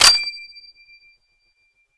assets/nx/nzportable/nzp/sounds/weapons/m1garand/clipout.wav at 29b8c66784c22f3ae8770e1e7e6b83291cf27485